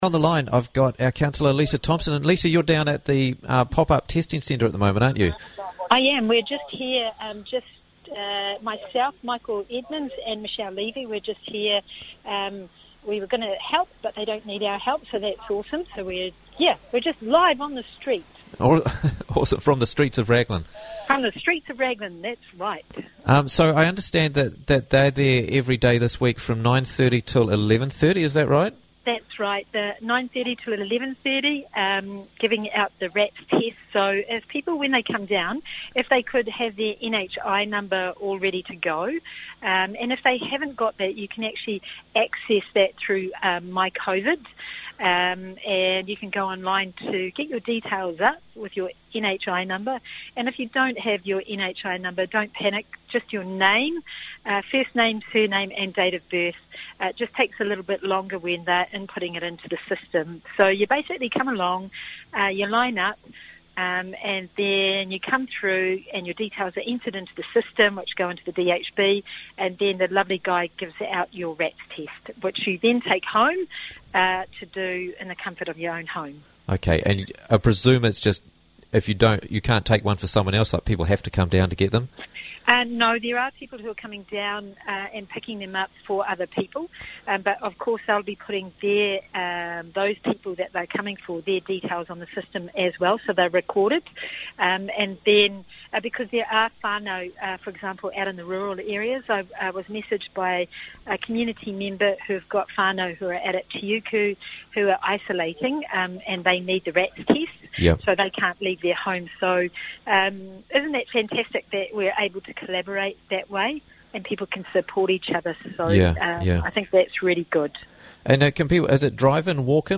RATs tests in Raglan - Interviews from the Raglan Morning Show